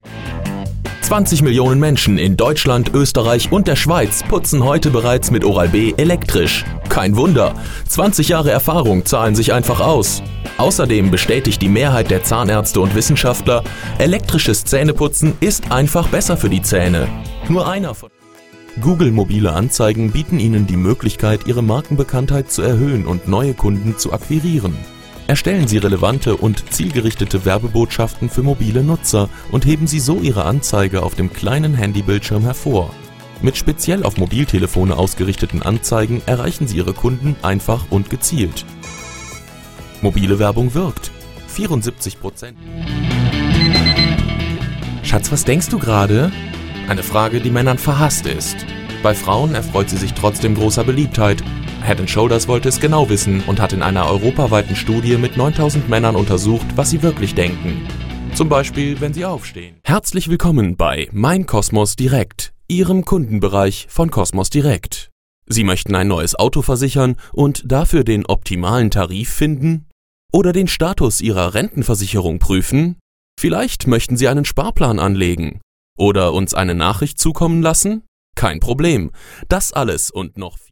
präsent, wandelbar, flexibel, spontan - Eigenes Studio mit ISDN, SourceConnect & Skype
Kein Dialekt
Sprechprobe: Industrie (Muttersprache):